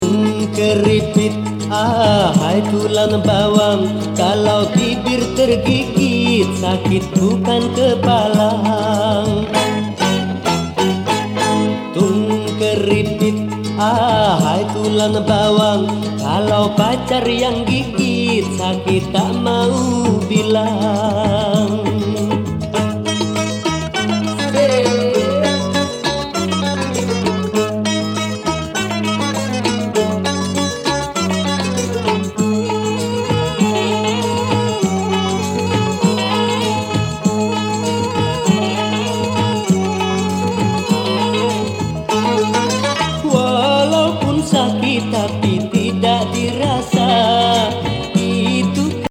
アシッディなアラビック闇鍋グルーブ!!